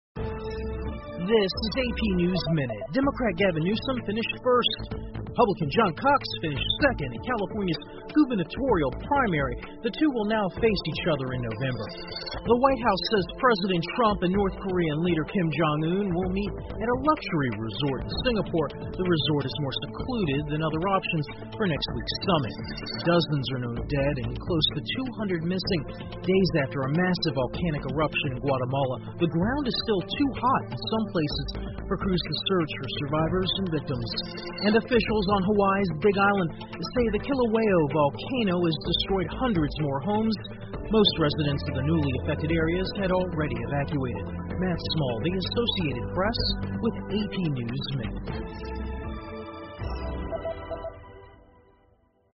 美联社新闻一分钟 AP 特金会选定豪华度假村作会场 听力文件下载—在线英语听力室